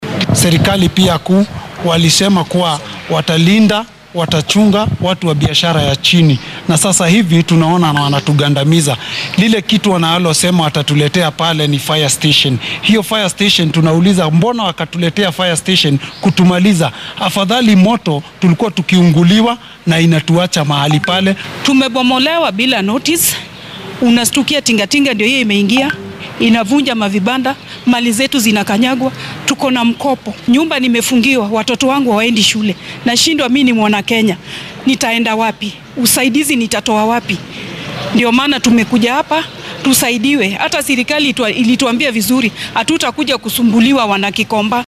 Qaar ka mid ah ganacsatadan oo warbaahinta la hadlay ayaa dareenkooda sidatan u muujiyay.